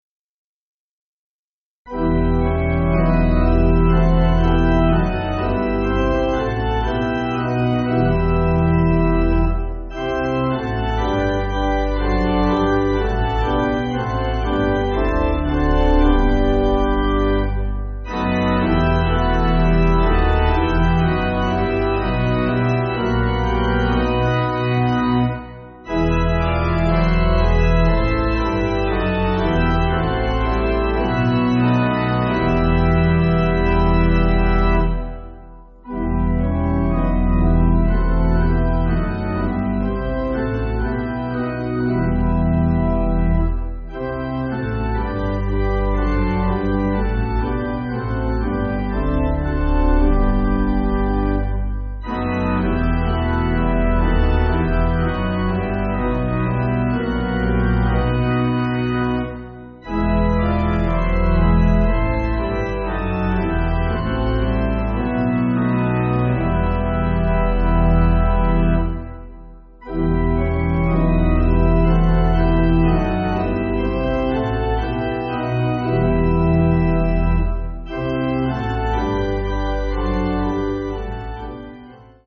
Organ
(CM)   4/Em